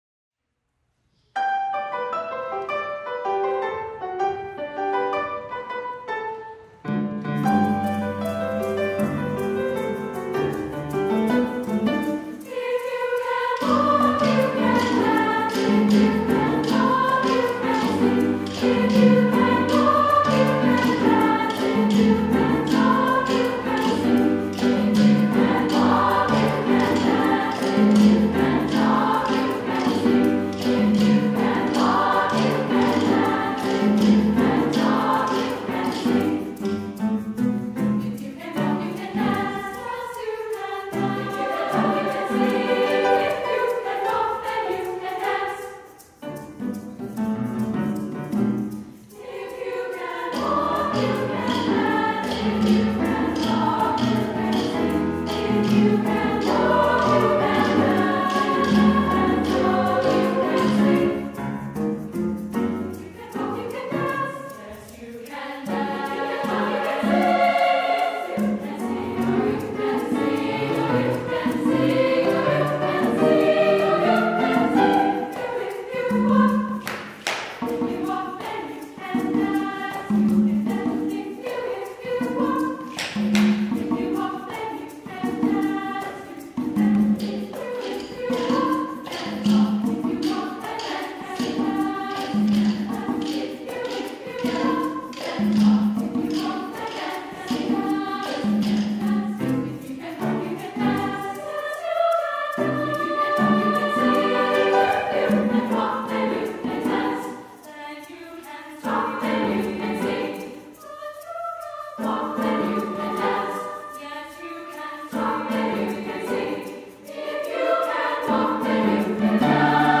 A contagiously joyful Latin dance song.
SSA, piano, claves